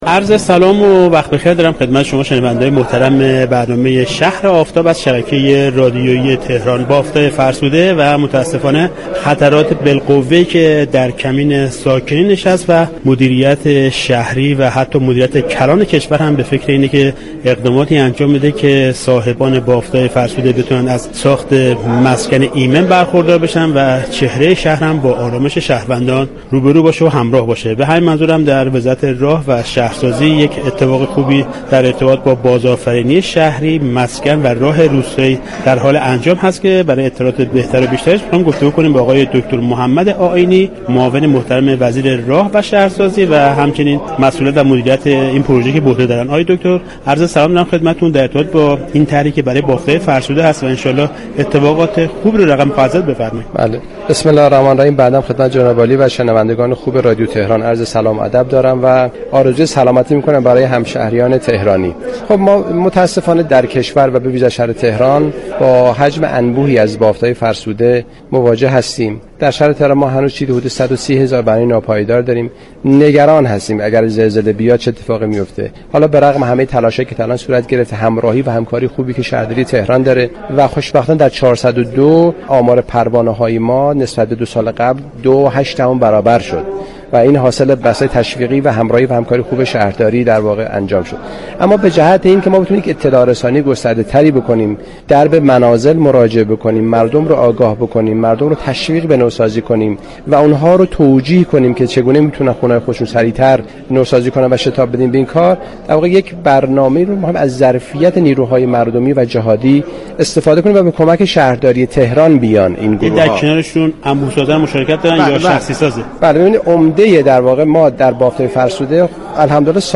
حدود 130 هزار بنای ناپایدار در تهران داریم به گزارش پایگاه اطلاع رسانی رادیو تهران، محمد آئینی معاون وزیر راه و شهرسازی در گفت و گو با «شهر آفتاب» درخصوص نوسازی بافت‌های فرسوده شهر تهران اظهار داشت: متاسفانه در كشور با حجم انبوهی از بافت فرسوده مواجه هستیم.